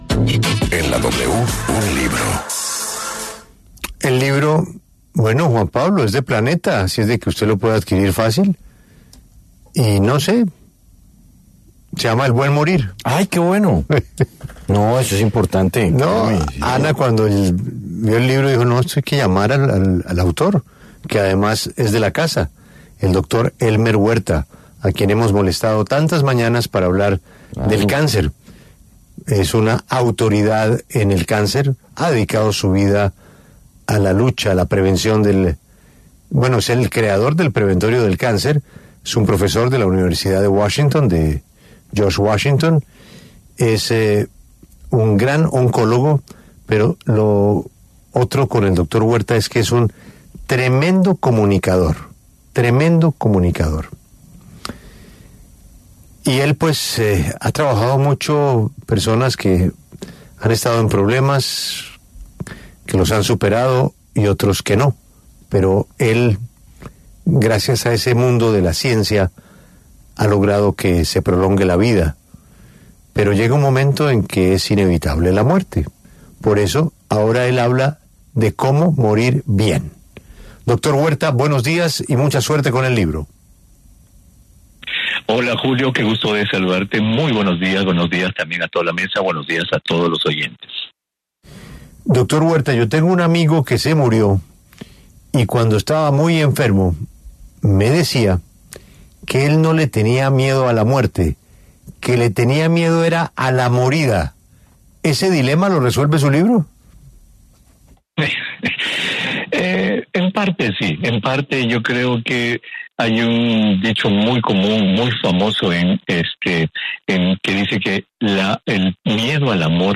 El doctor Elmer Huerta conversó con La W sobre su nuevo libro, ‘El buen morir’, el cual busca ayudar al lector a entender y afrontar la muerte.